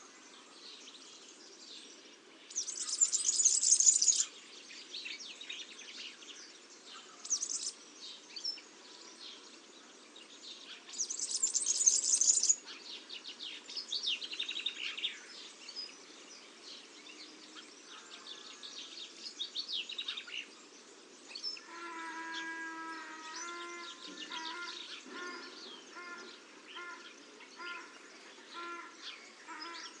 serin-cini.mp3